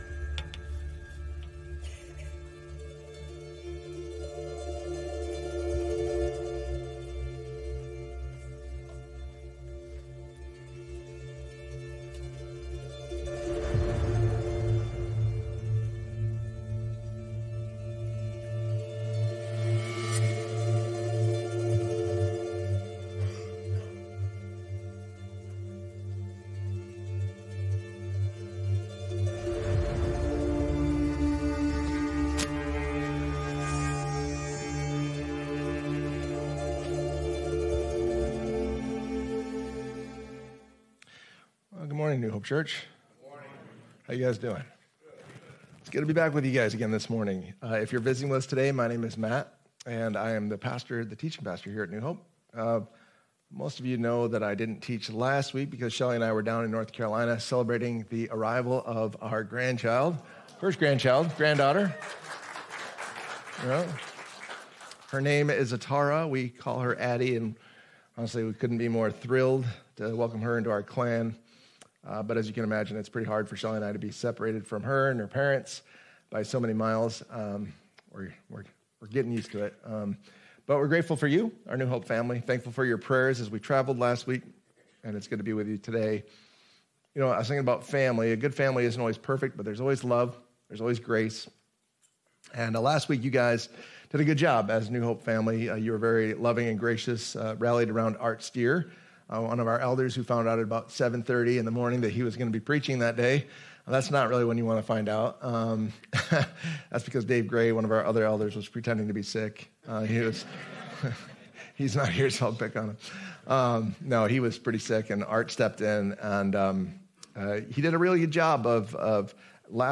Sermons | New Hope Church